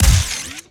GUNTech_Sci Fi Shotgun Fire_03_SFRMS_SCIWPNS.wav